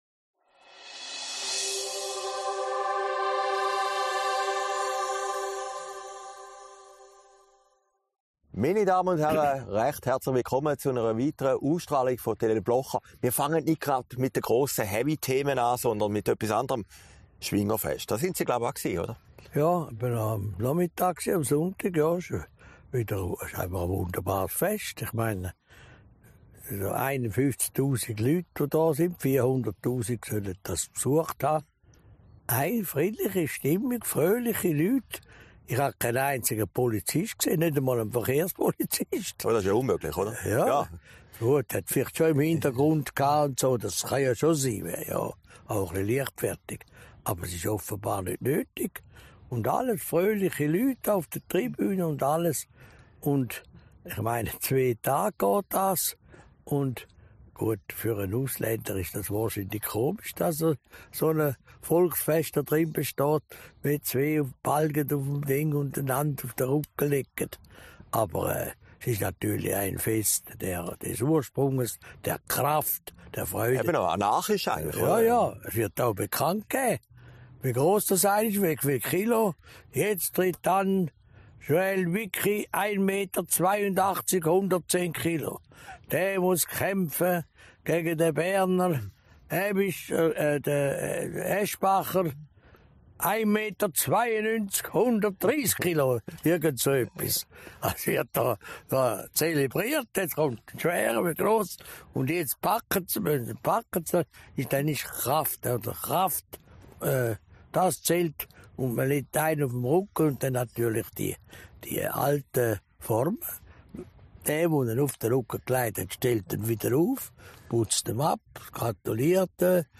Sendung vom 2. September 2022, aufgezeichnet in Herrliberg